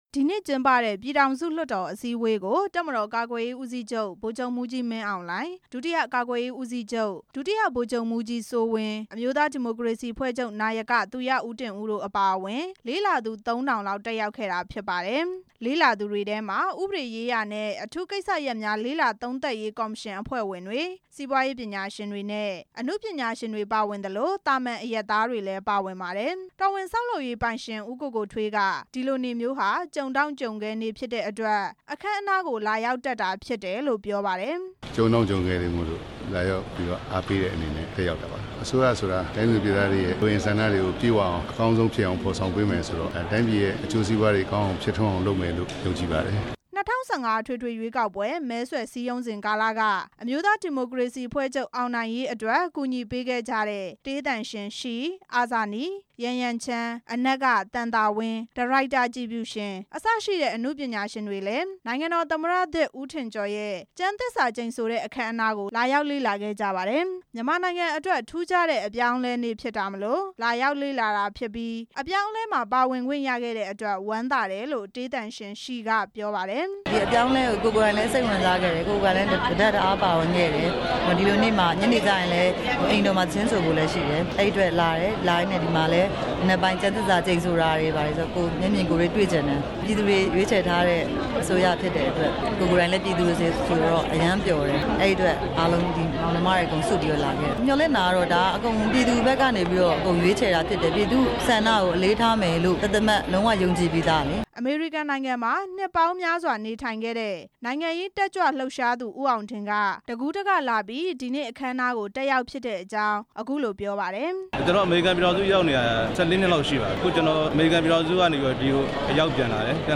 လေ့လာသူတွေရဲ့ပြောကြားချက်တွေ